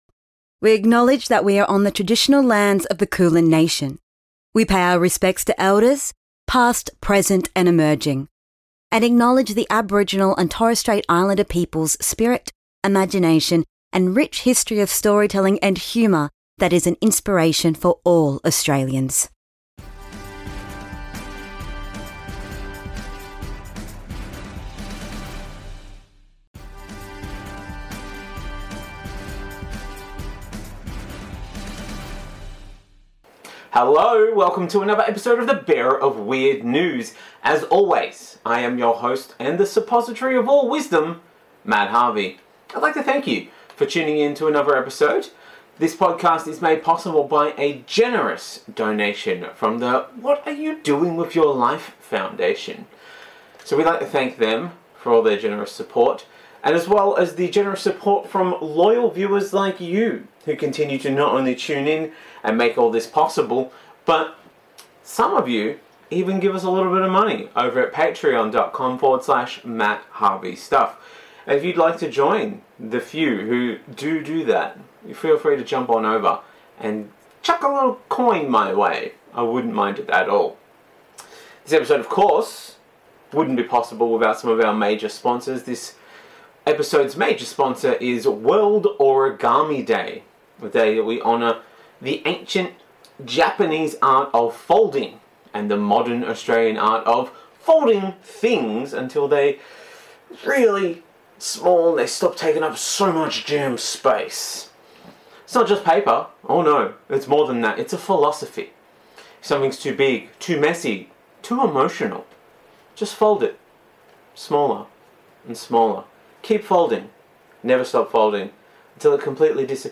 Australian news round-up